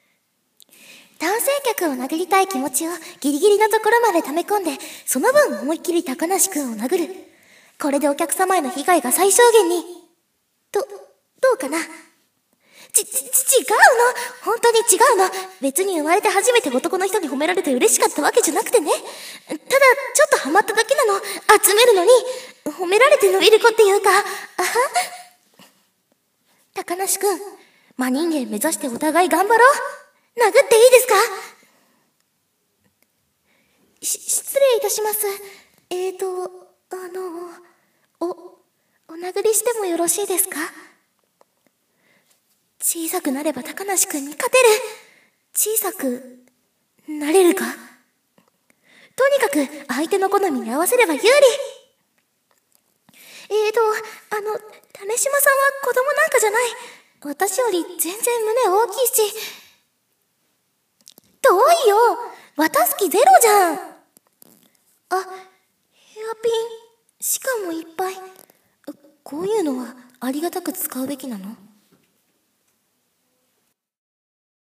伊波まひる 声真似